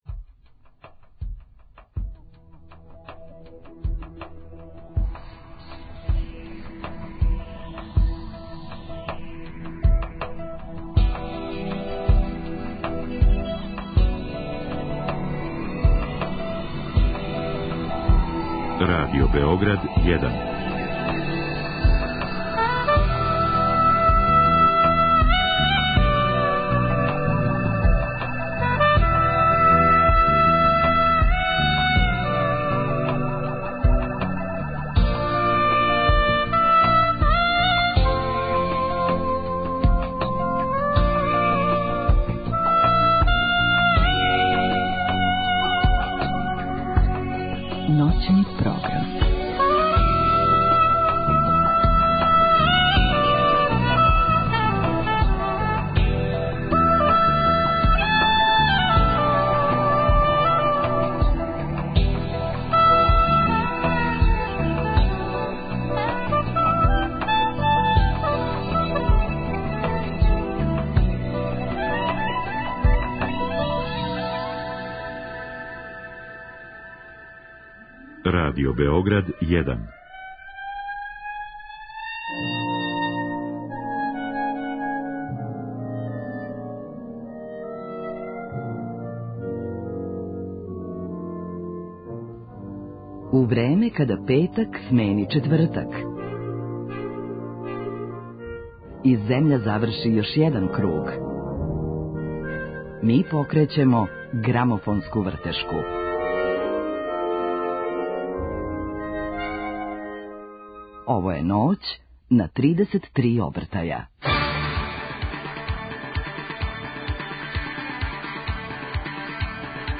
Ноћас ће диско кугла пратити наш темпо.
Слушамо плоче које су обележиле диско еру!